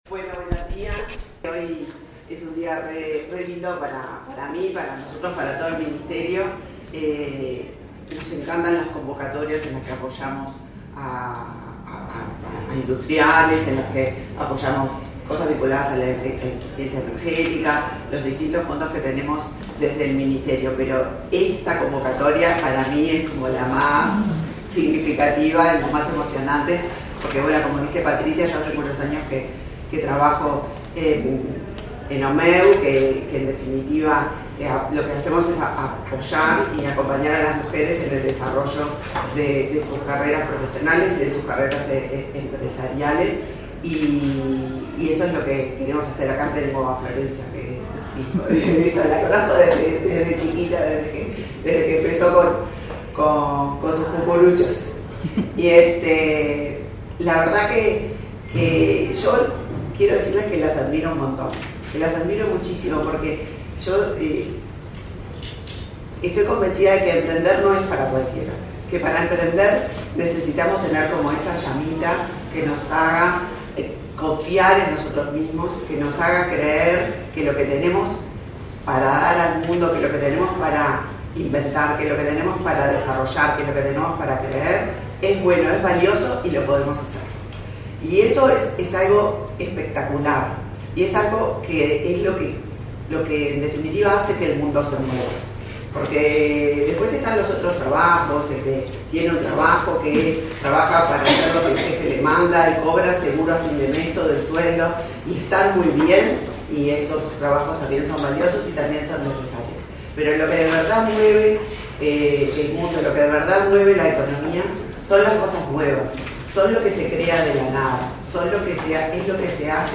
Acto de entrega de diplomas a emprendedoras
En el evento disertaron la ministra Elisa Facio y la directora del Instituto Nacional de las Mujeres, Mónica Bottero.